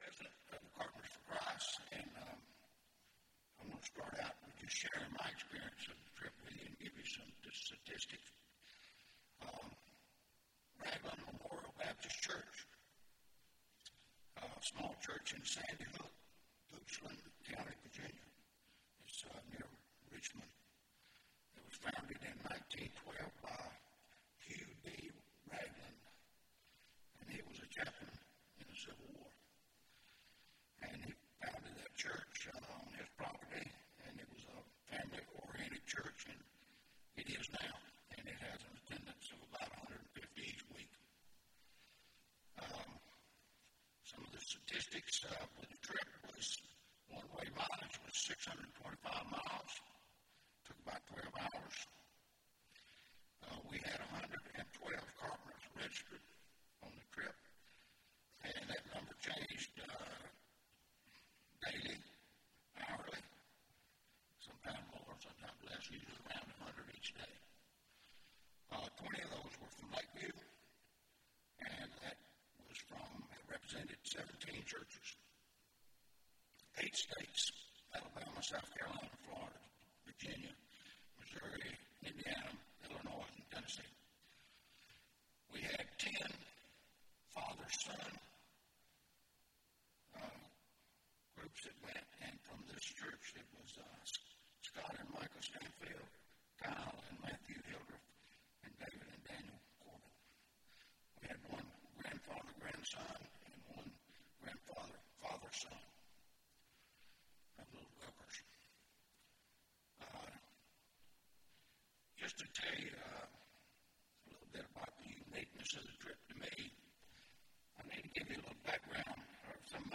Testimony Service – Carpenter’s for Christ